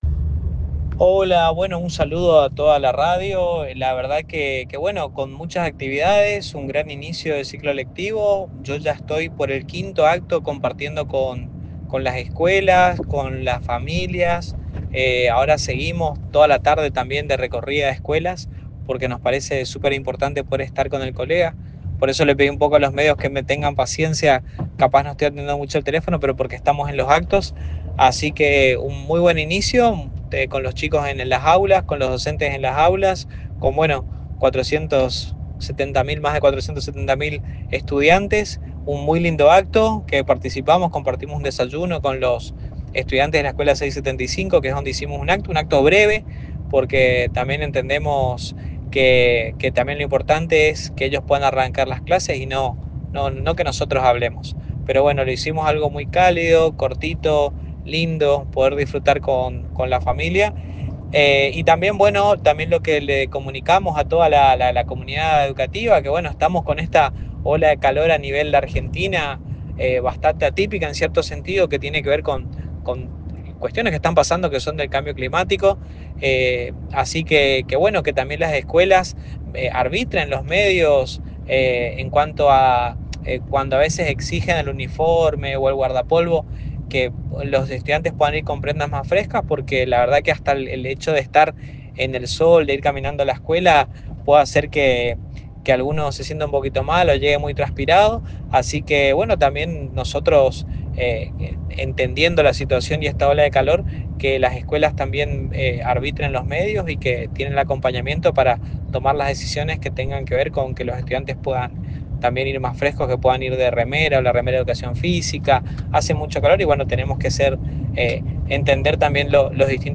(audio con declaraciones del ministro Educación de Misiones, Ramiro Aranda)